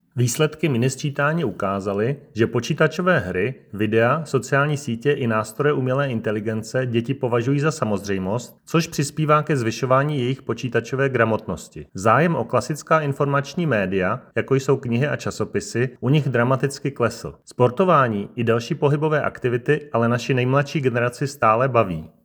csu_tz250128_petina_deti_necte_knihy_a_vetsina_jiz_vyuzila_ai_ukazaly_vysledky_miniscitani_2025.docx Vyjádření Marka Rojíčka, předsedy Českého statistického úřadu (soubor mp3) Prezentace z tiskové konference